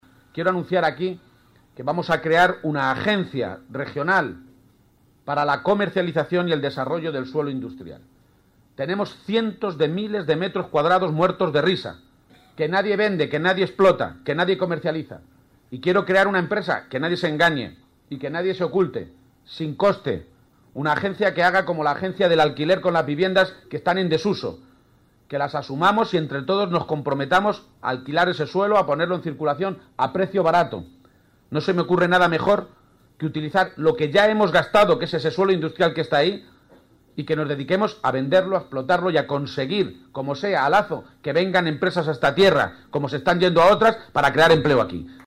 Audio Page en La Solana 1